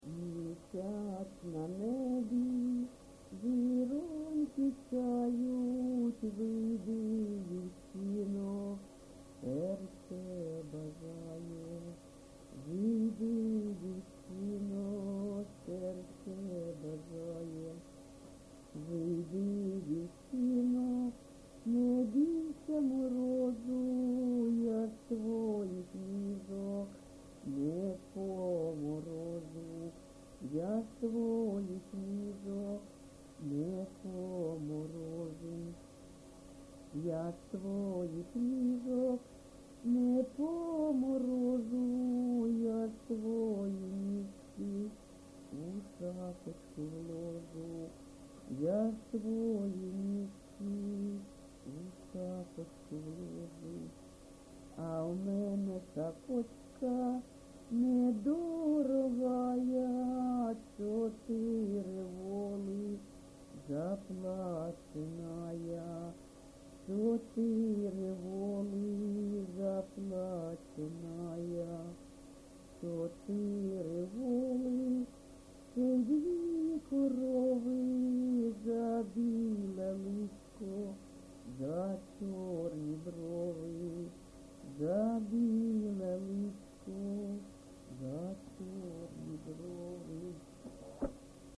ЖанрПісні з особистого та родинного життя
Місце записум. Бахмут, Бахмутський район, Донецька обл., Україна, Слобожанщина